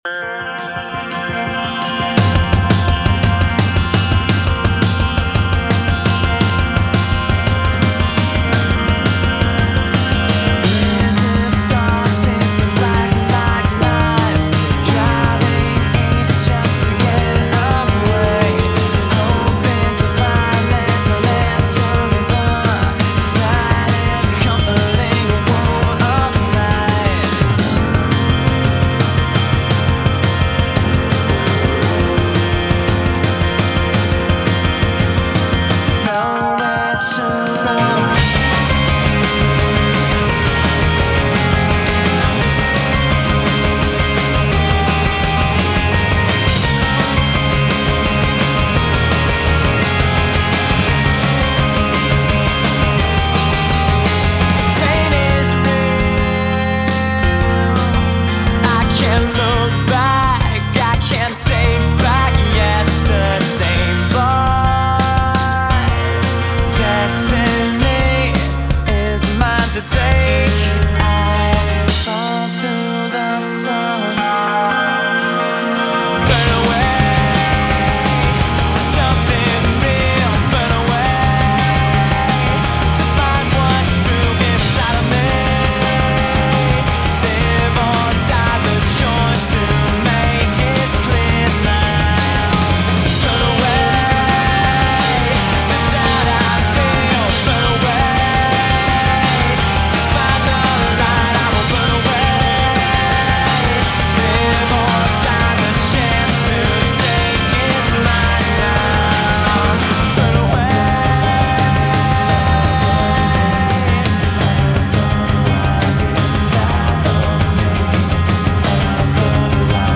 BPM85-170
MP3 QualityMusic Cut